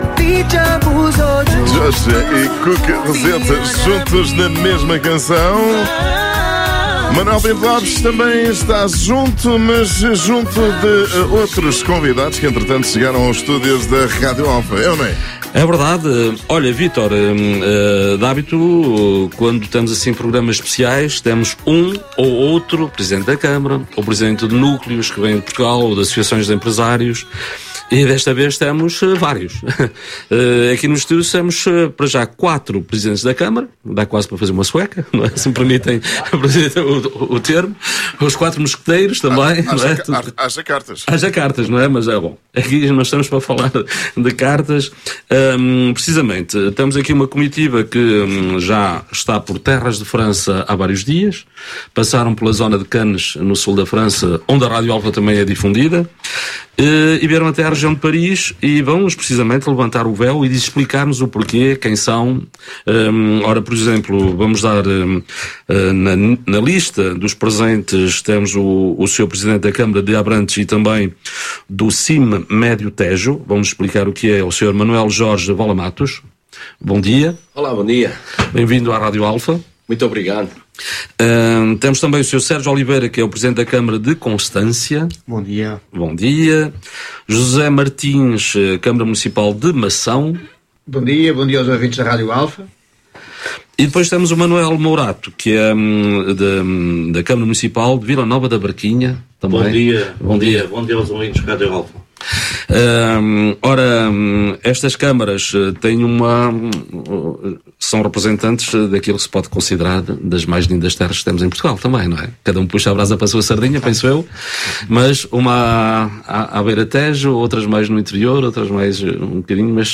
Hoje, a emissão « Espaço Aberto » da Rádio Alfa recebeu uma delegação vinda diretamente de Portugal, composta por representantes de autarquias e entidades empresariais da região do Médio Tejo.